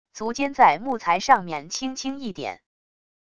足尖在木材上面轻轻一点wav音频